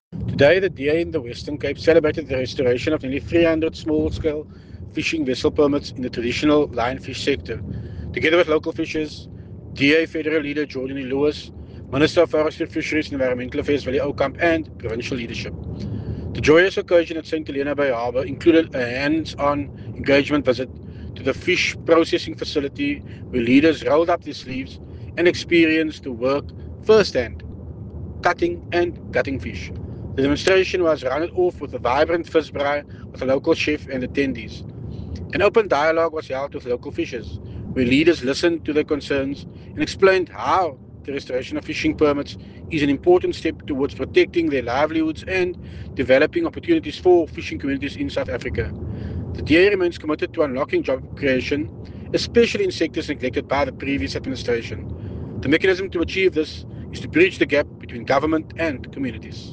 soundbite by Tertuis Simmers
Tertuis-Simmers-in-St-Helena-Bay.mp3